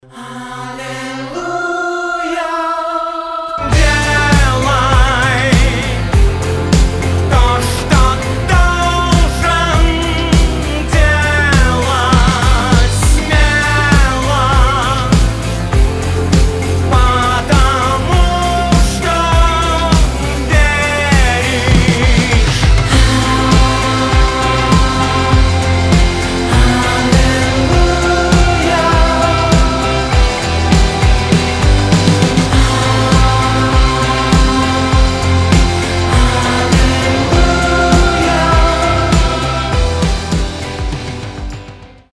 alternative rock